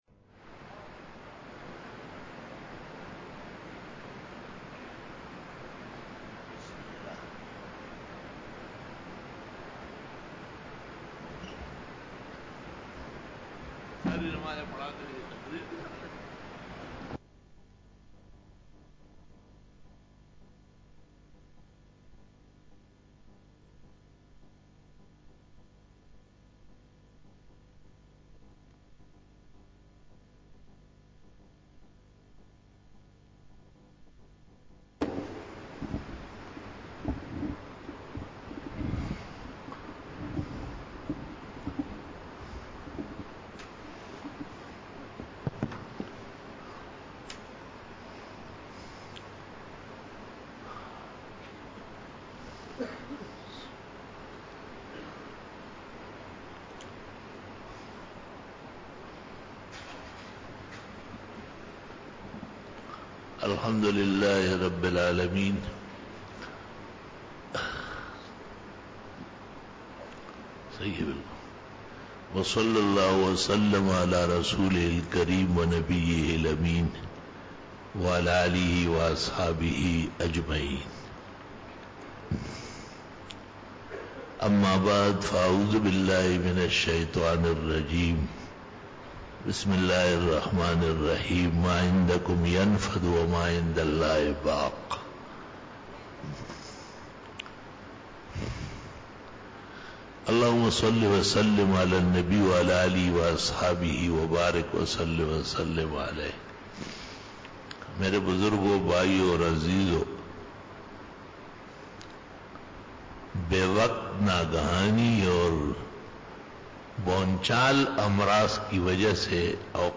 17 BAYAN E JUMA TUL MUBARAK (26 April 2019) (19 Shaban 1440H)